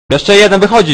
Worms speechbanks
uh-oh.wav